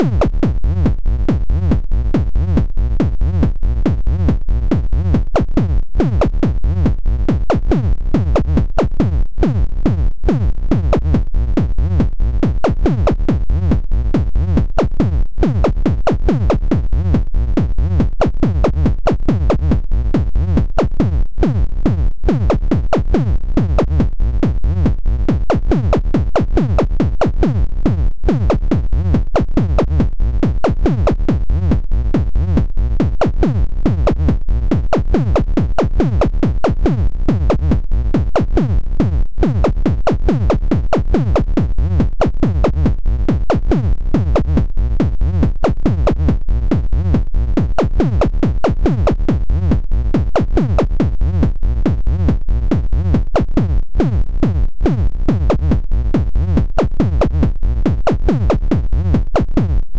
Pseudo-random rhythm, two beat types, bassy sound gliding around.